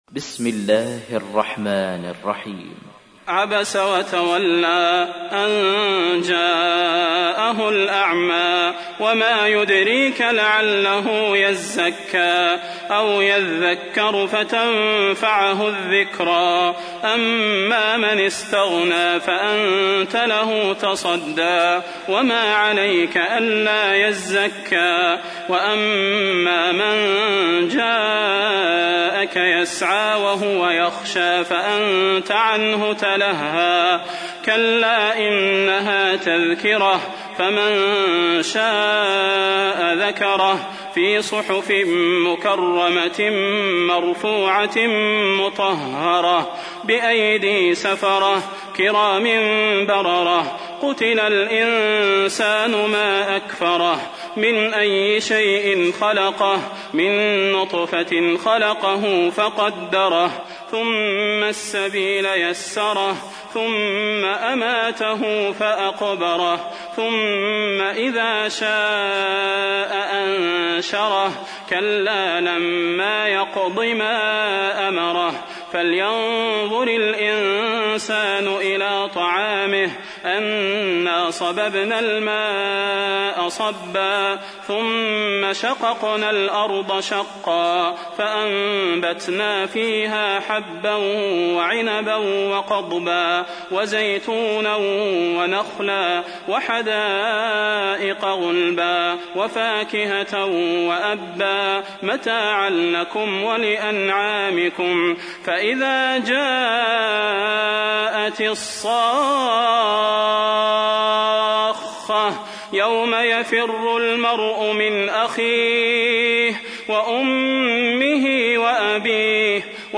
تحميل : 80. سورة عبس / القارئ صلاح البدير / القرآن الكريم / موقع يا حسين